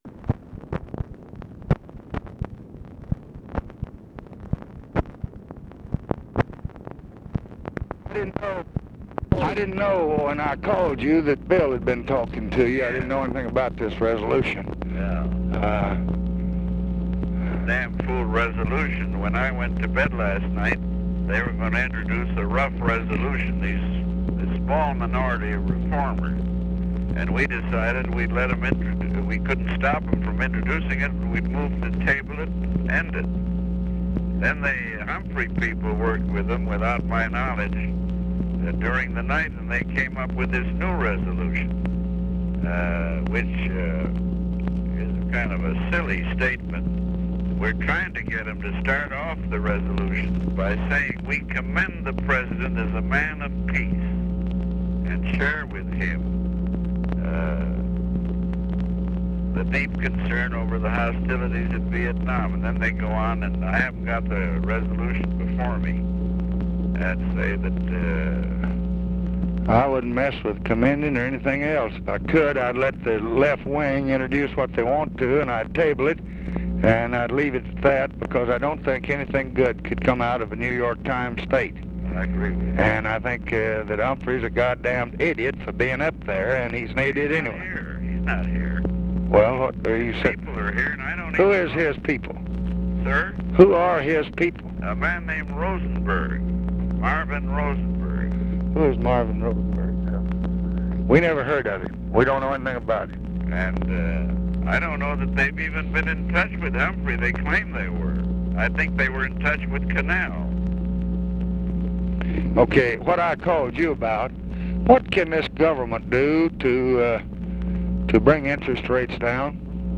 Conversation with EDWIN WEISL and OFFICE CONVERSATION, September 7, 1966
Secret White House Tapes